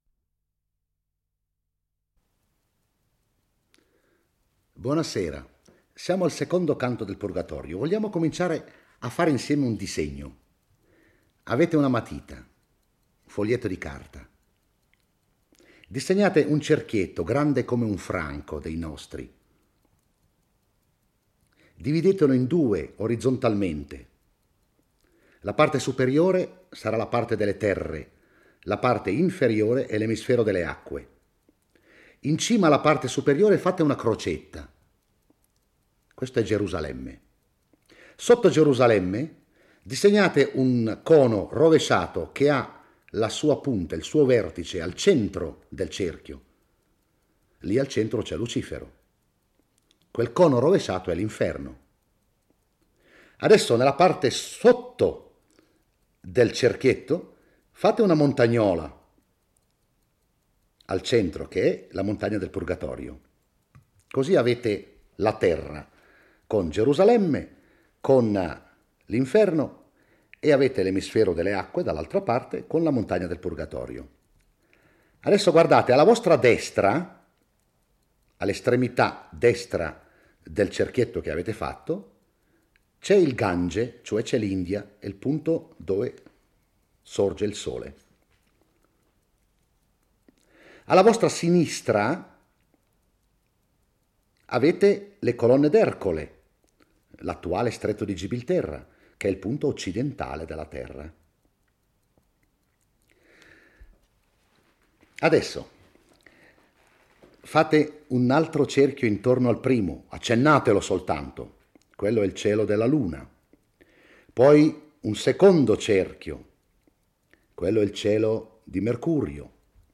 legge e commenta il II canto del Purgatorio. Dante e Virgilio si trovano nella parte bassa della montagna del Purgatorio, che comprende la spiaggia e la prima fascia.